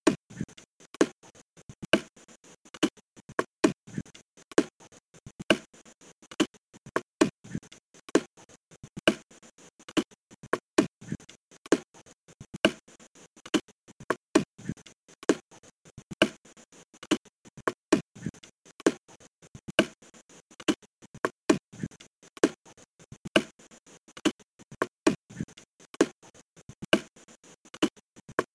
Inciso 3 – en 15/8